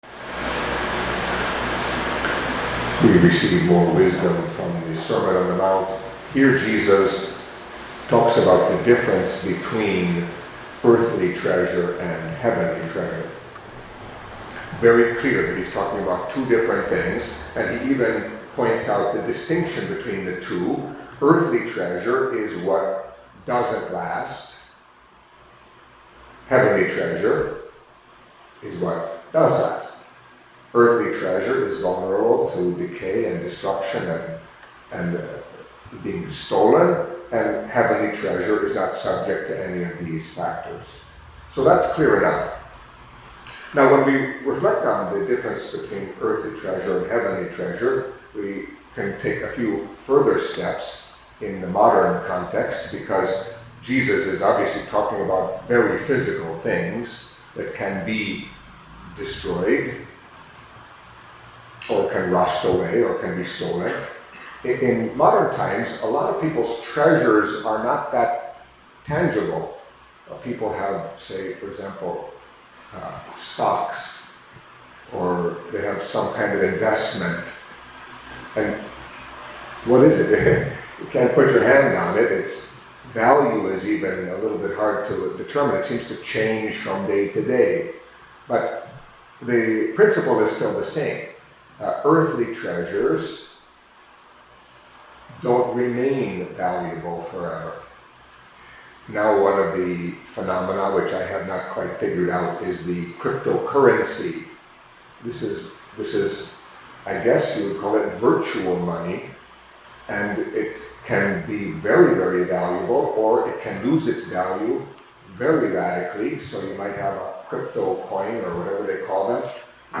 Catholic Mass homily for Friday of the Eleventh Week in Ordinary Time